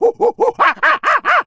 SFX_LOE_022_Play.ogg